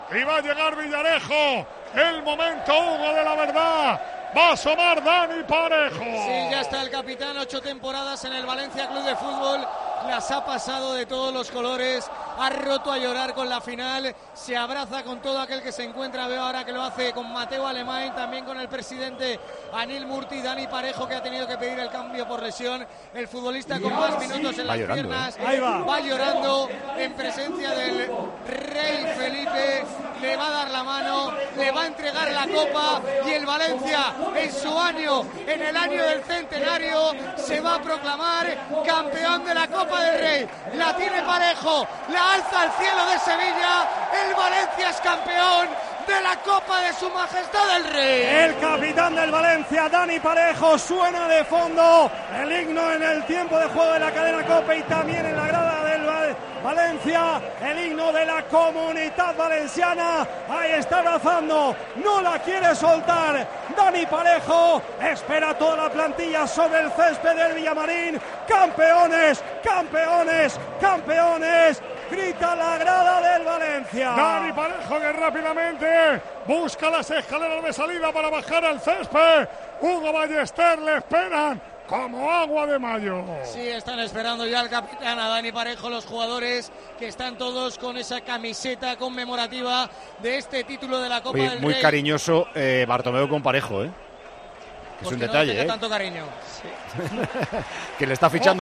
Así sonó en Tiempo de Juego la entrega de la Copa del Rey al Valencia
narraron la entrega del trofeo al Valencia tras ganar al Barcelona en la final de la Copa del Rey.